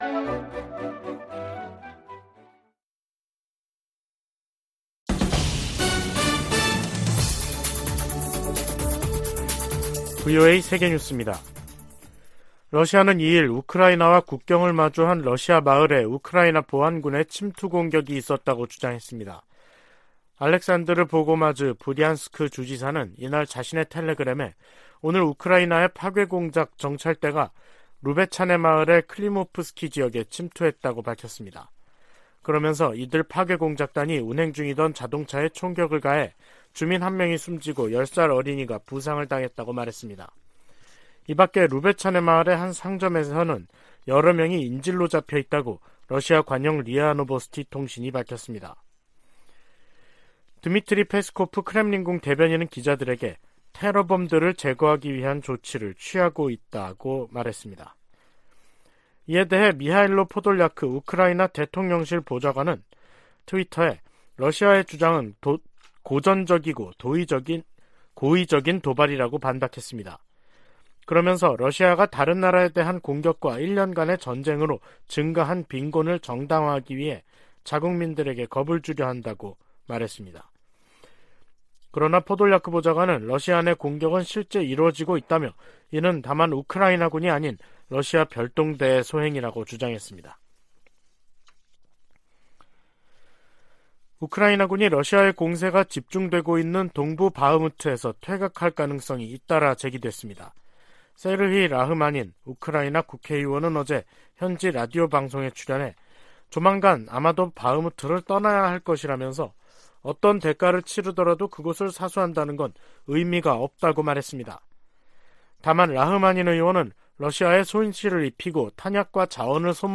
VOA 한국어 간판 뉴스 프로그램 '뉴스 투데이', 2023년 3월 2일 3부 방송입니다. 미 국무부는 윤석열 한국 대통령의 3∙1절 기념사가 한일관계의 미래지향적 비전을 제시했다며 환영의 뜻을 밝혔습니다. 최근 실시한 미한일 탄도미사일 방어훈련이 3국 협력을 증진했다고 일본 방위성이 밝혔습니다. 미 하원에 한국전쟁 종전 선언과 평화협정 체결, 미북 연락사무소 설치 등을 촉구하는 법안이 재발의됐습니다.